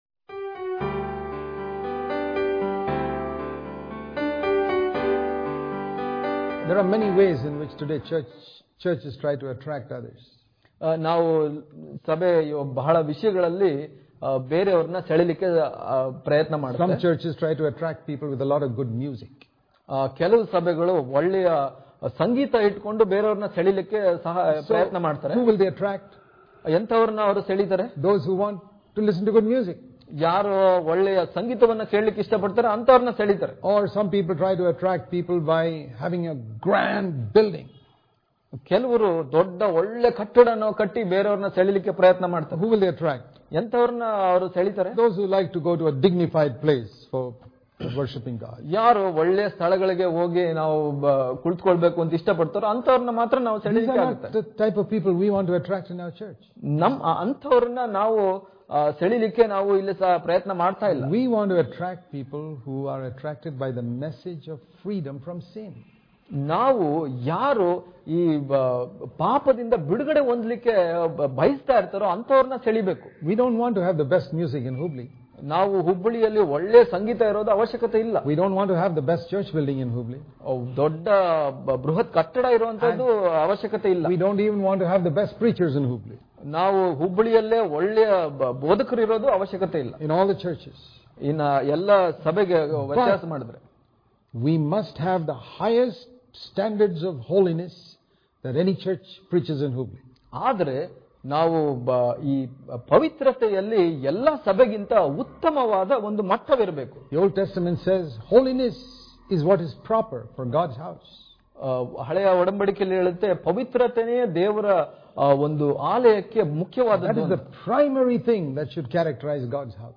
June 17 | Kannada Daily Devotion | Unity And Holiness Are The Picture Of The Church Daily Devotions